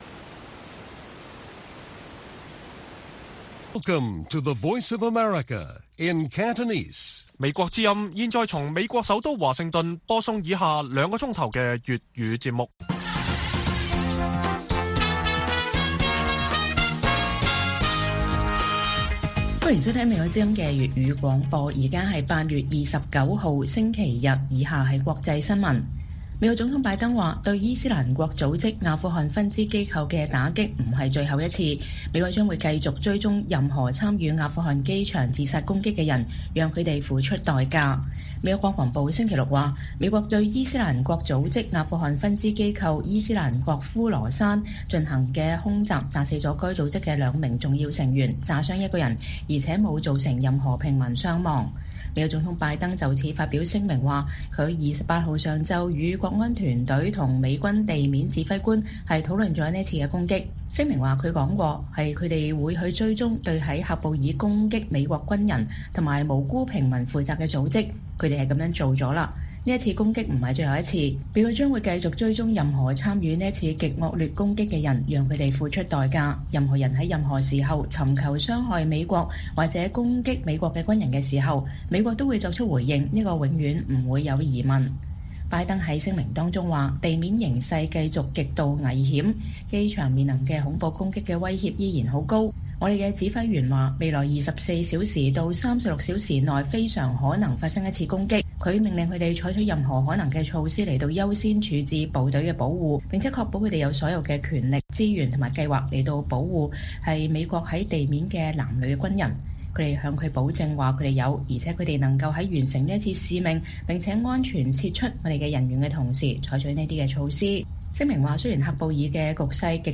粵語新聞 晚上9-10點 拜登：對伊斯蘭國的打擊不是最後一次